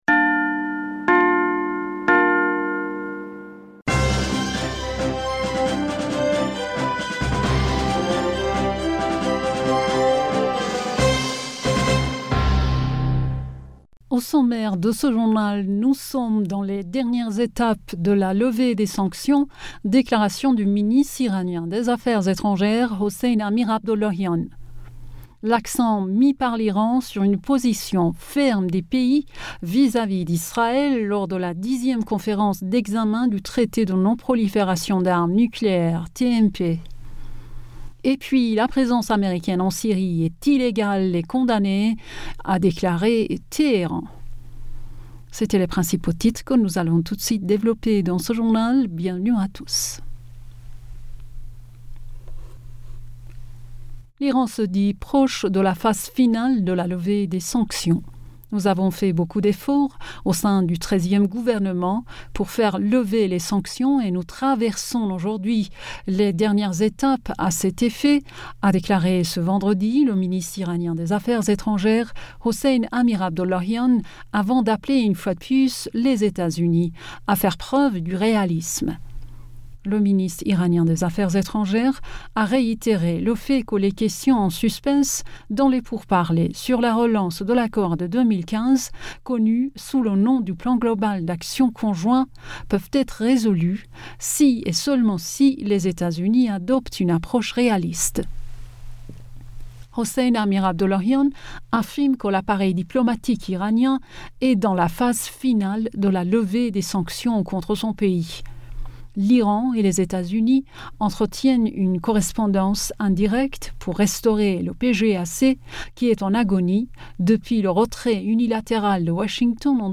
Bulletin d'information Du 26 Aoùt